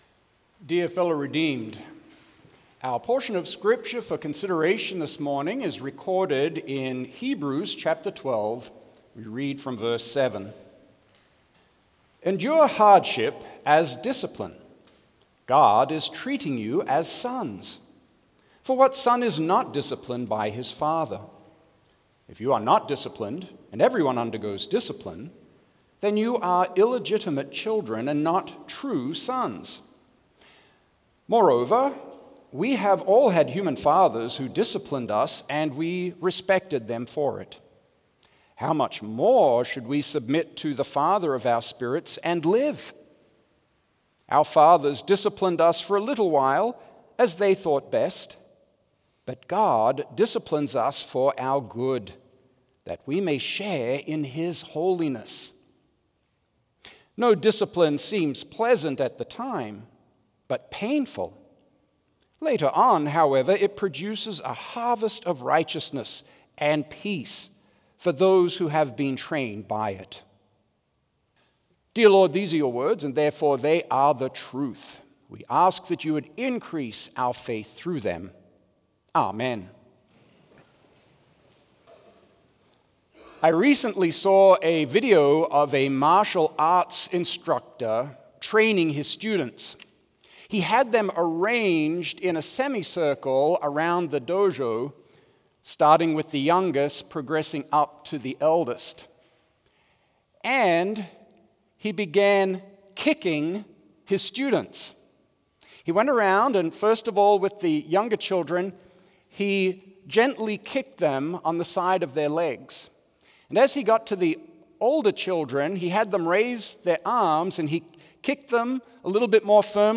Complete service audio for Chapel - Wednesday, March 19, 2025